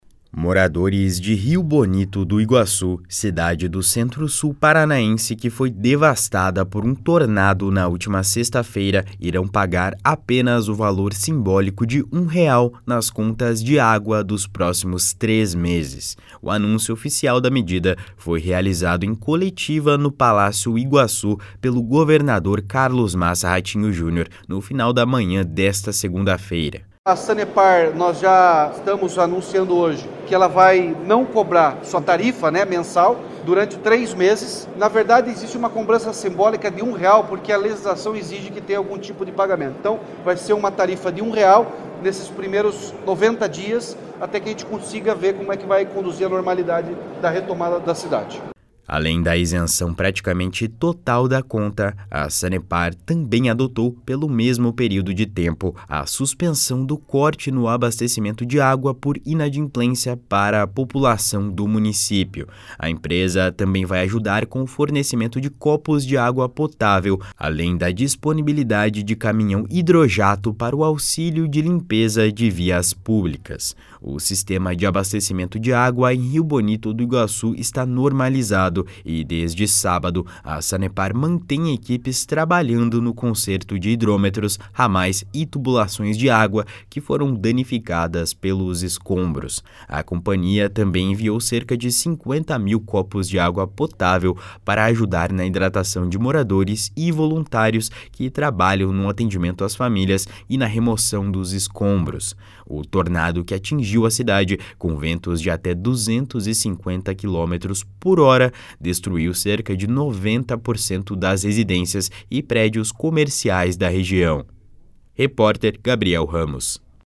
Os moradores de Rio Bonito do Iguaçu, cidade do Centro-Sul paranaense que foi devastada por um tornado na última sexta-feira, irão pagar apenas o valor simbólico de 1 real nas contas de água dos próximos três meses. O anúncio oficial da medida foi realizado em coletiva no Palácio Iguaçu pelo governador Carlos Massa Ratinho Junior, no final da manhã desta segunda-feira. // SONORA RATINHO JUNIOR //